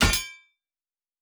Fantasy Interface Sounds
Weapon UI 01.wav